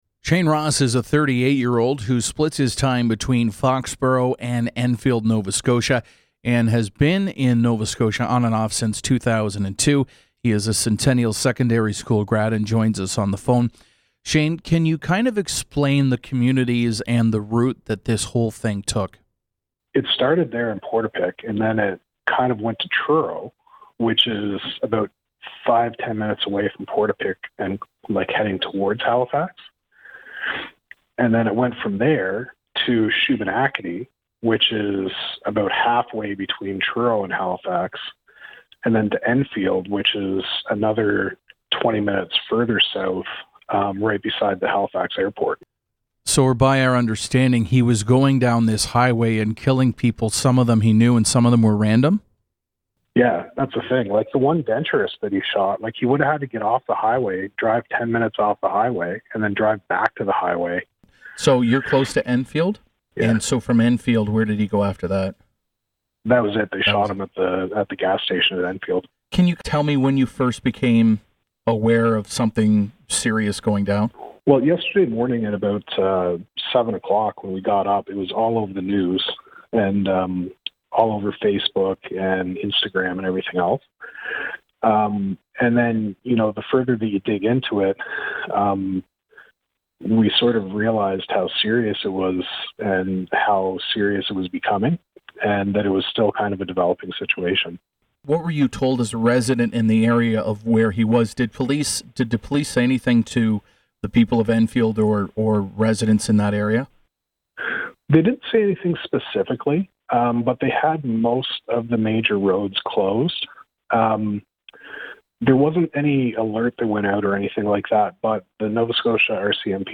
The full interview